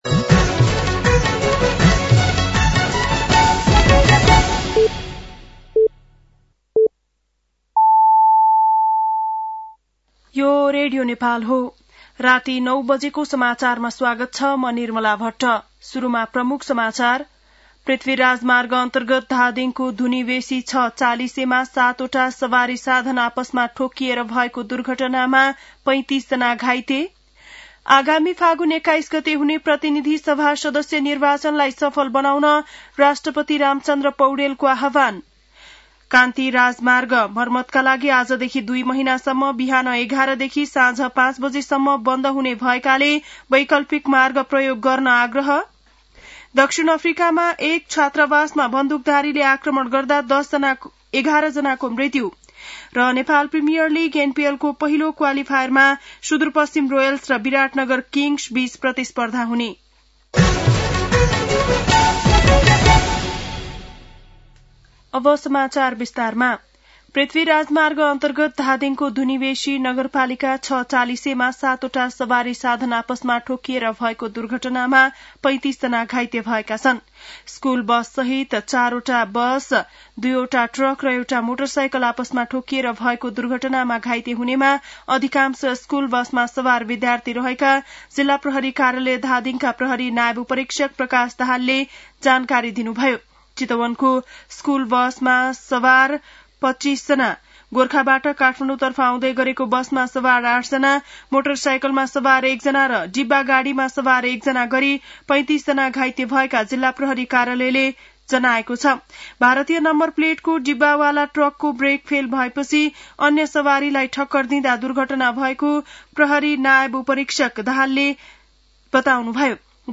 बेलुकी ९ बजेको नेपाली समाचार : २० मंसिर , २०८२